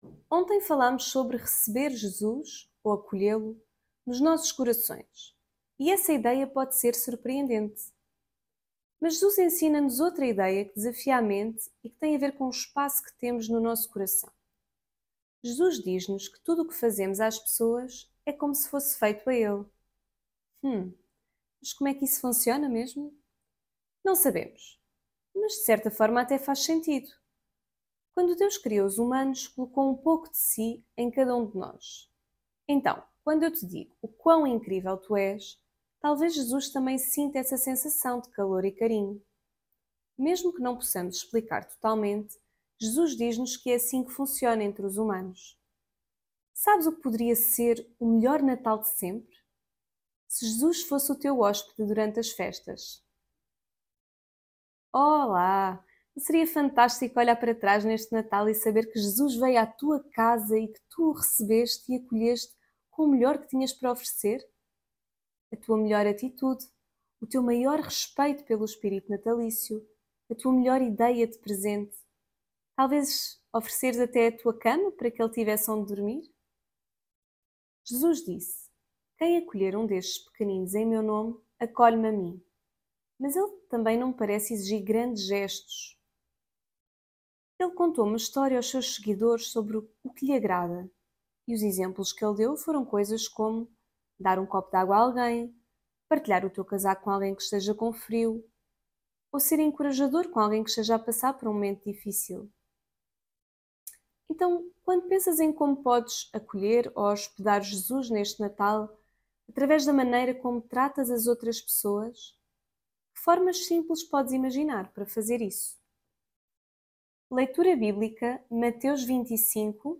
Devocional Coríntios